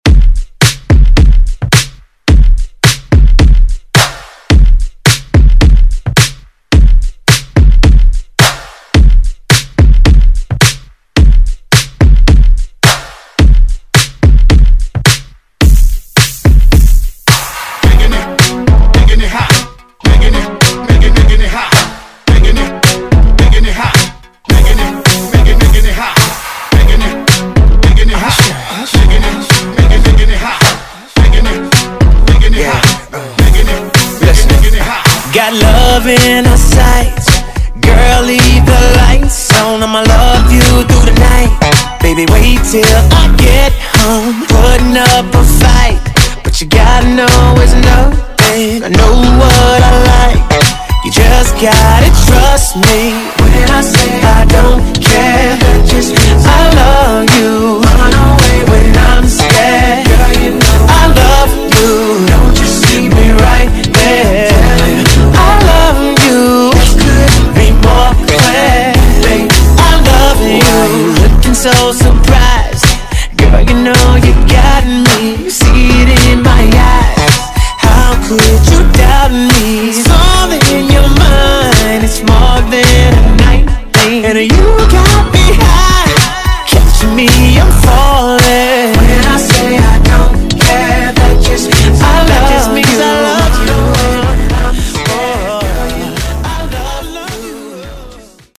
Genre: DANCE
Clean & Dirty BPM: 128 Time